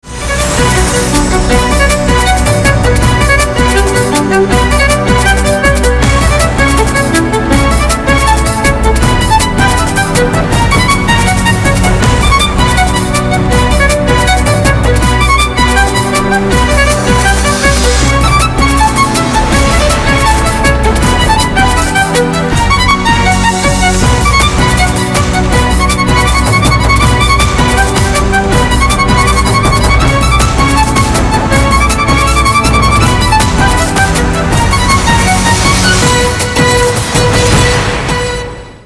• Качество: 256, Stereo
без слов
скрипка
инструментальные
Красивая игра на скрипке